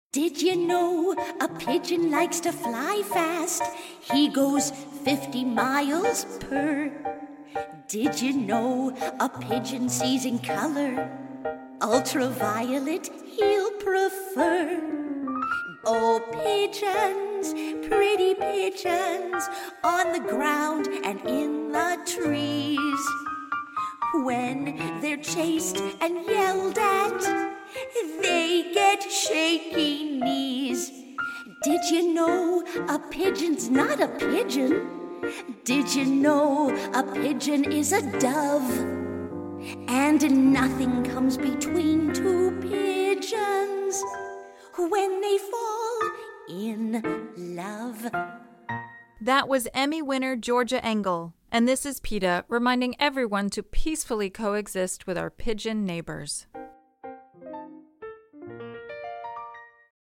Instructions for Downloading This Radio PSA Audio File